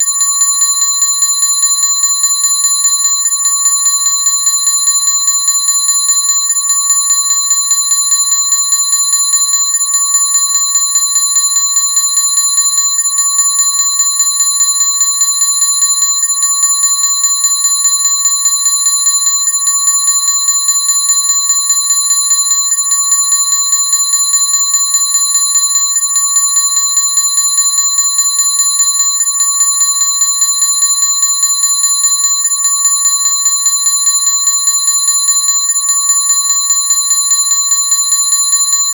朝の目覚まし用アラーム音に最適な連続した高音のベル音が起こしてくれる。金属目覚ましの音です。（キンキンキン…）